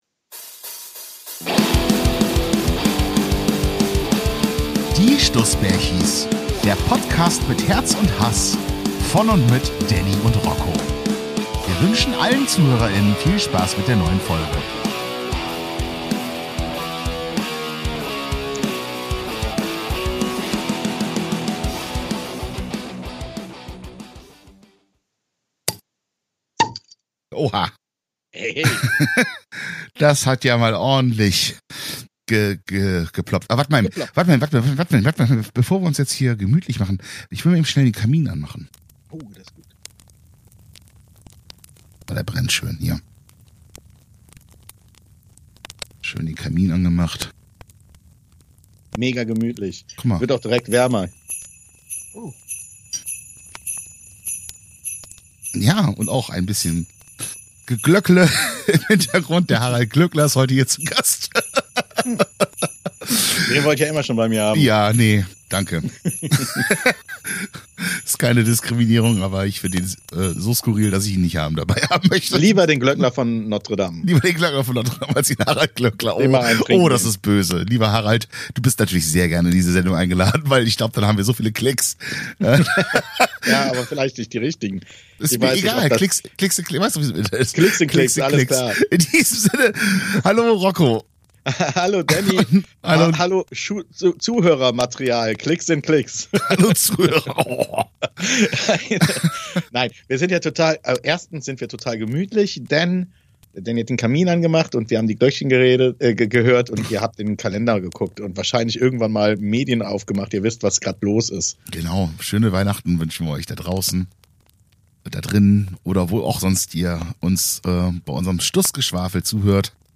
Beschreibung vor 3 Jahren Das Holz im Kamin brennt leise knisternd, in der Ferne hört man die Glöckchen leise klingeln und der Duft von Kerzen und weihnachtlichen Gerüchen liegt in der Luft. Eine herrliche Zeit um zu ranten, hassen, lieben und vermissen!